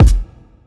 MB Kick (6).wav